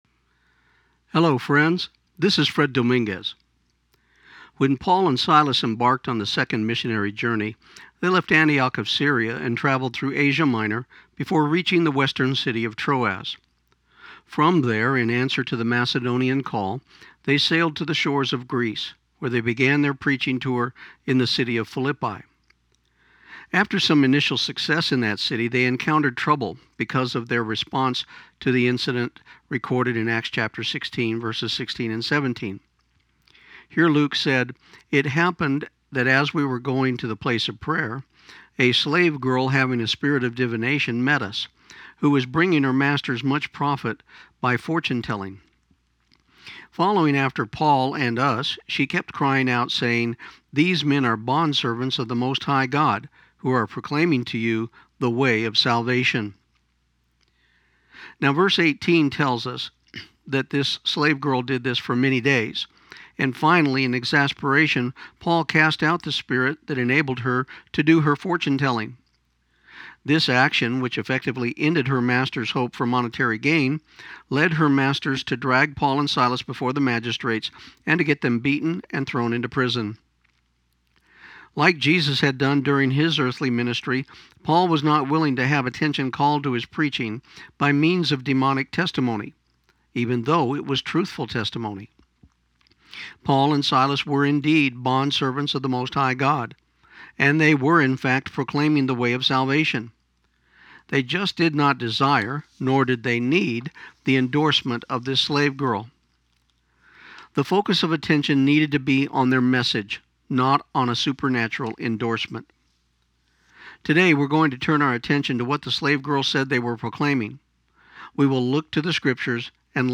This program aired on KIUN 1400 AM in Pecos, TX on August 31, 2015.